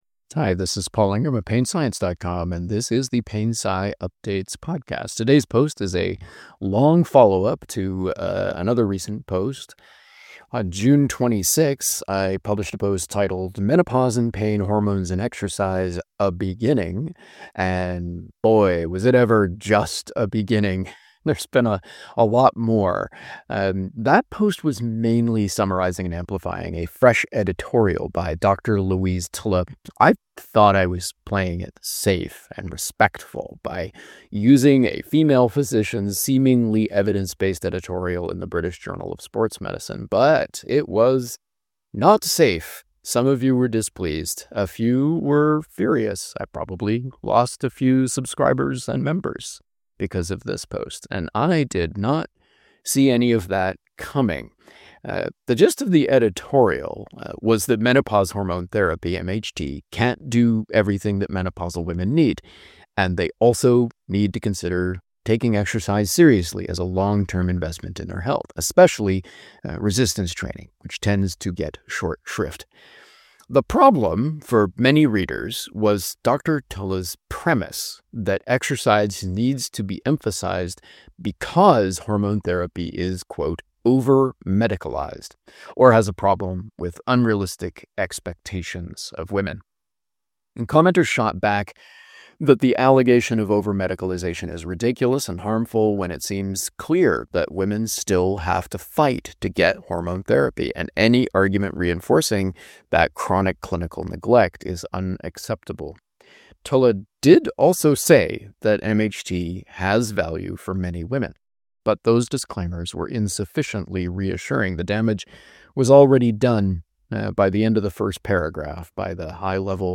Play the members-only audio version of this post. Audio versions usually include some digressions.